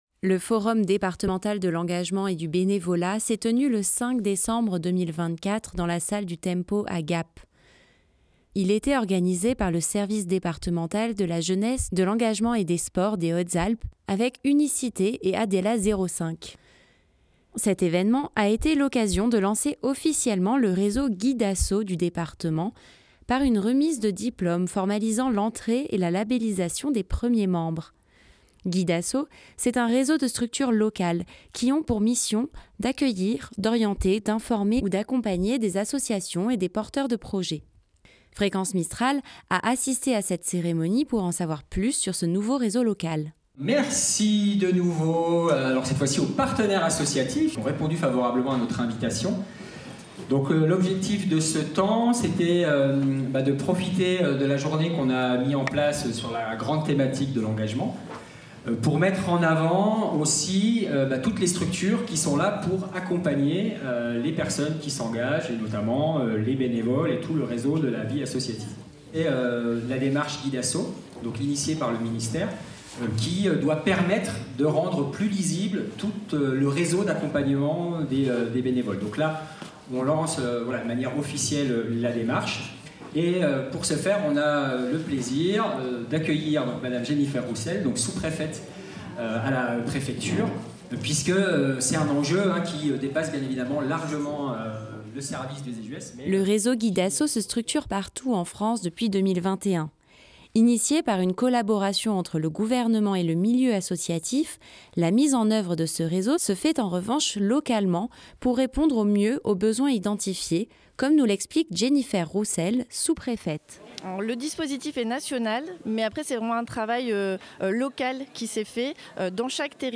Cet événement a été l'occasion de lancer officiellement le réseau Guid'asso du département par une remise de diplome formalisant l'entrée et la labellisation de ses premiers membres. Fréquence Mistral a assisté à cette cérémonie pour en savoir plus sur ce nouvel outil.